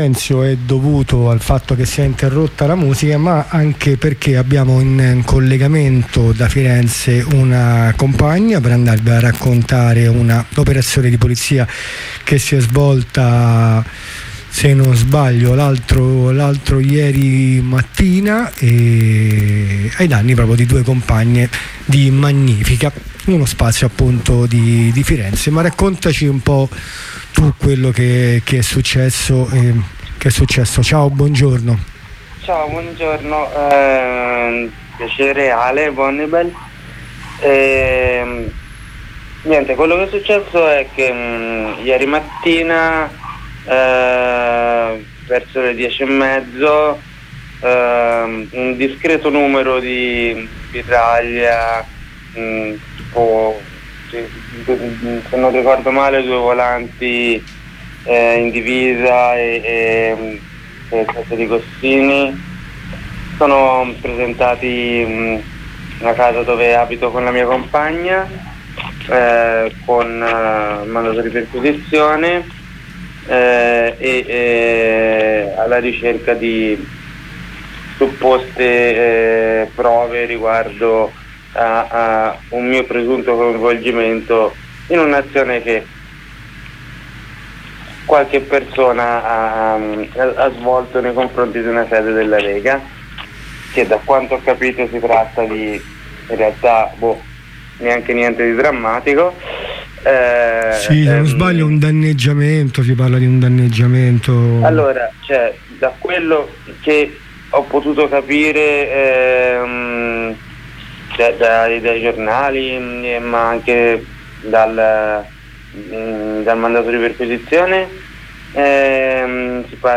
La bislacca operazione di polizia vede nel verbale del fermo anche il non riconoscere che la compagna è una donna trans e indicata come "uomo travestito da donna". Ne parliamo con la compagna fermata che ci racconta tutto l'accaduto.